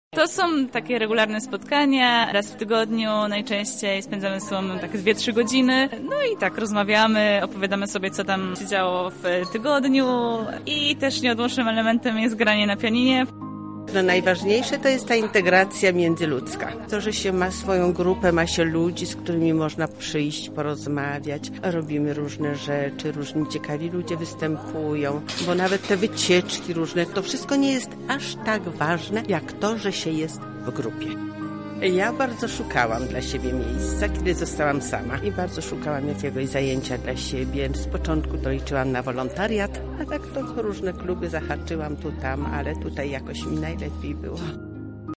Z uczestnikami świątecznego spotkania rozmawiali nasi reporterzy: